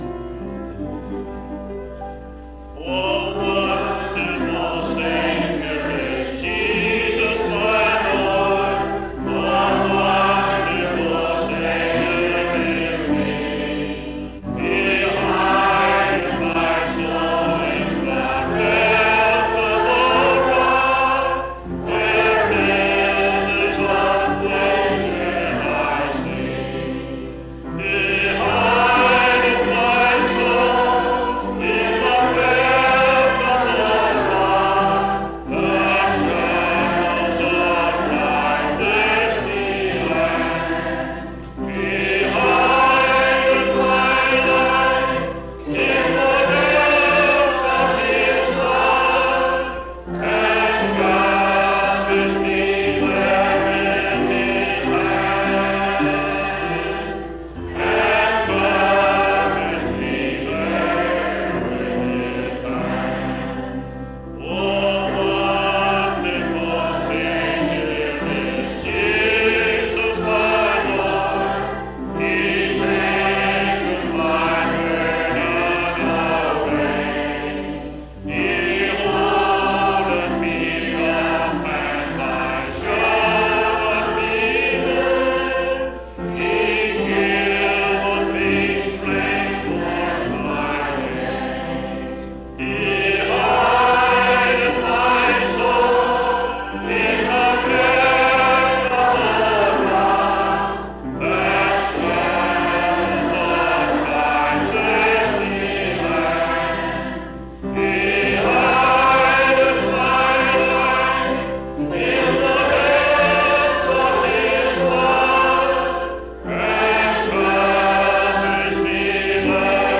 What a Wonderful Savior is Jesus Item c3a80ae83c77faeebd460f9e21201f3e8708d244.mp3 Title What a Wonderful Savior is Jesus Creator Congregation Description This recording is from the Calvary Methodist Church Revival.